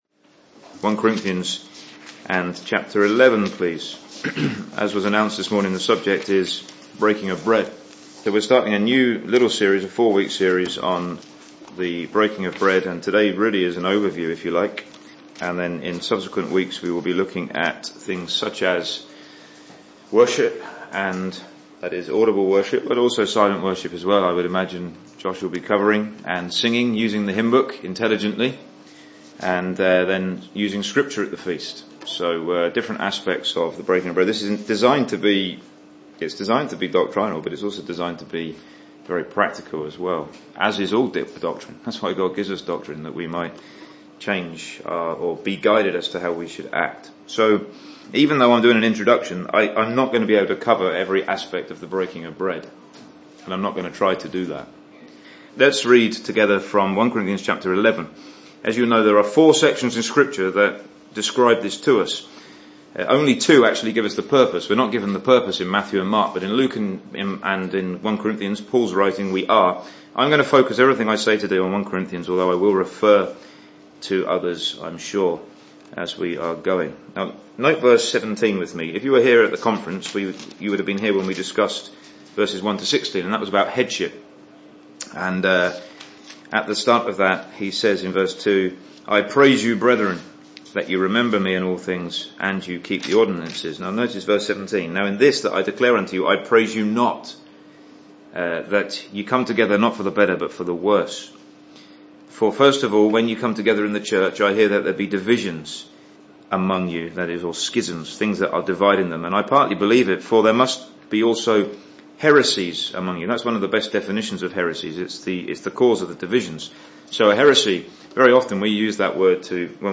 He also delves into history and reveals some interesting facts as to how this central Christian privilege has been viewed over the centuries (Message preached Sept 4th 2016)
Assembly Teaching